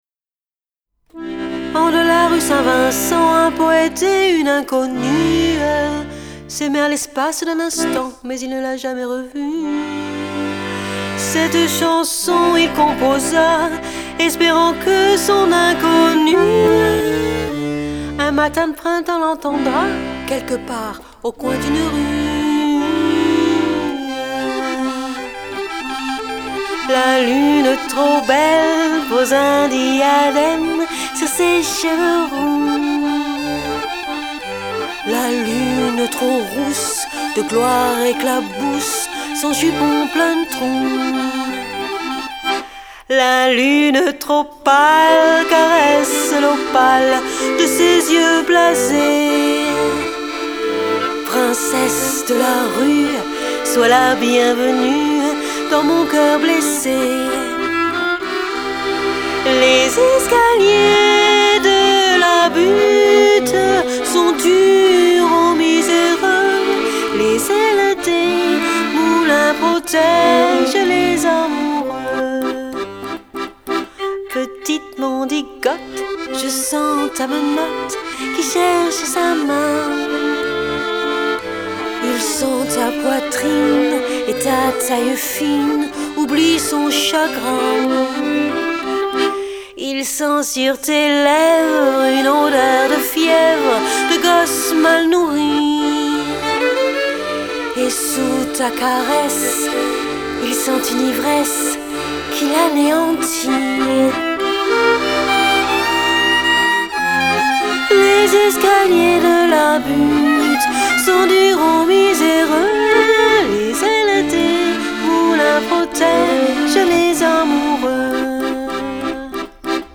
Solo Accordion